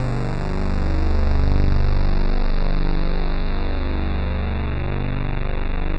Index of /90_sSampleCDs/Trance_Explosion_Vol1/Instrument Multi-samples/Angry Trance Pad
G1_angry_trance_pad.wav